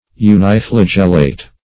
Search Result for " uniflagellate" : The Collaborative International Dictionary of English v.0.48: Uniflagellate \U`ni*fla*gel"late\, a. [Uni- + flagellate.] (Biol.) Having but one flagellum; as, uniflagellate organisms.